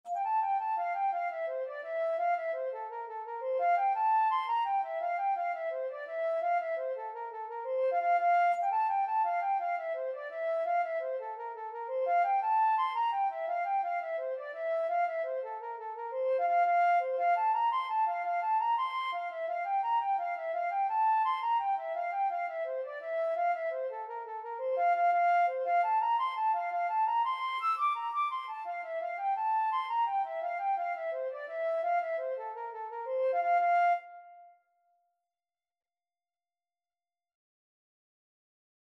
6/8 (View more 6/8 Music)
F major (Sounding Pitch) (View more F major Music for Flute )
Flute  (View more Intermediate Flute Music)
Traditional (View more Traditional Flute Music)